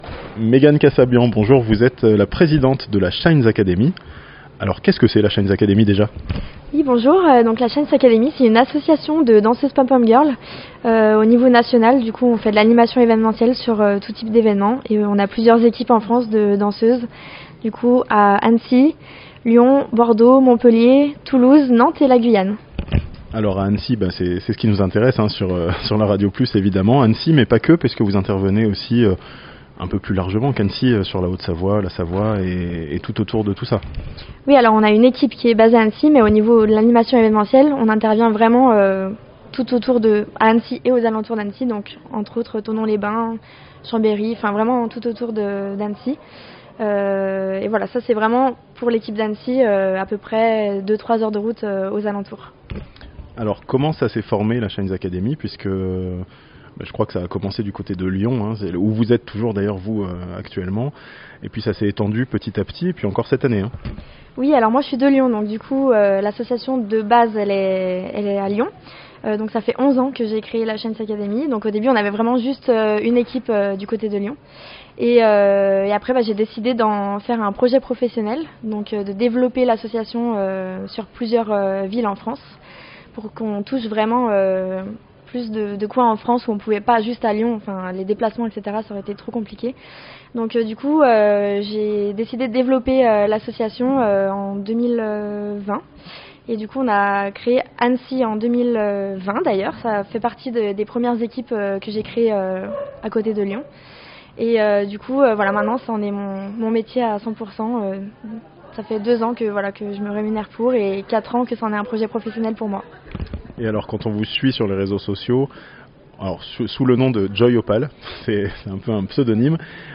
Shines Academy : des pompom girls made in Haute-Savoie (interview)